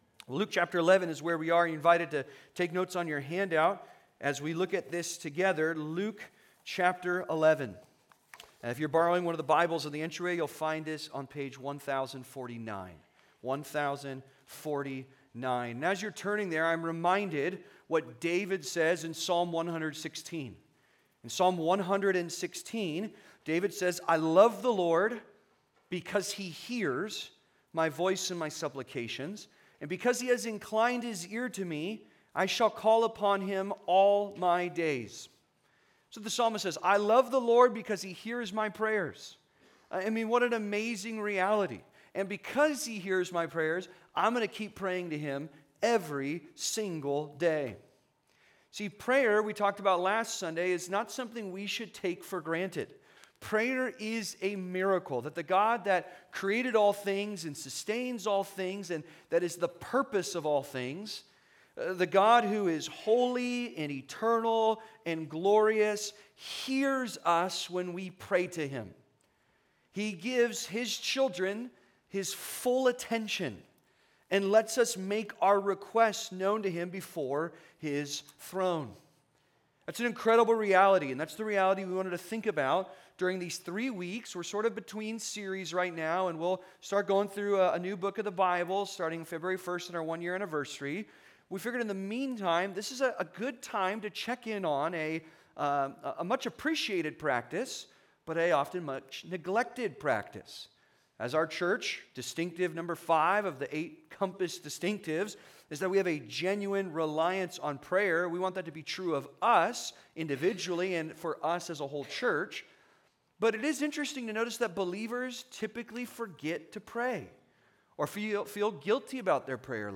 Praying Together: Devoted and Dependent (Sermon) - Compass Bible Church Long Beach